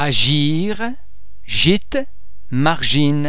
Please be mindful of the fact that all the French sounds are produced with greater facial, throat and other phonatory muscle tension than any English sound.
The French letter [g] before [e] or [ i ] is normally pronounced as the [s] in the English words treasure, pleasure etc.